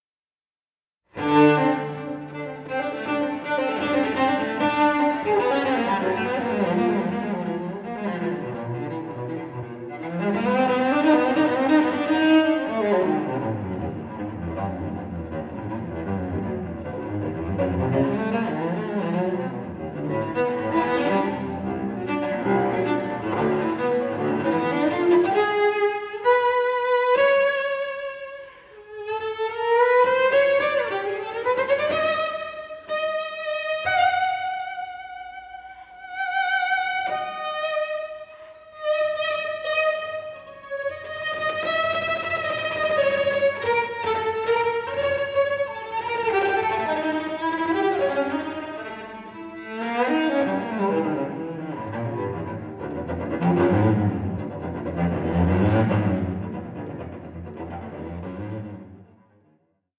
solo cello